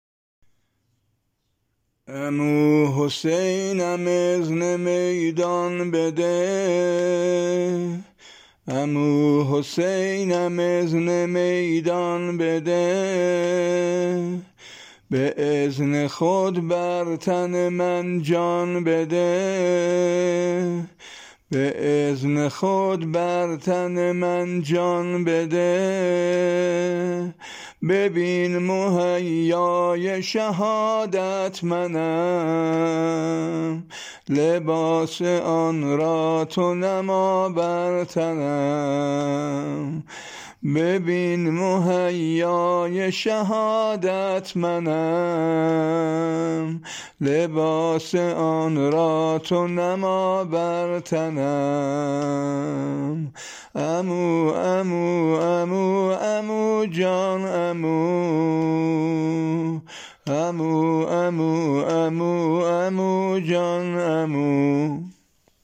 نوحه شهادت قاسم ابن الحسن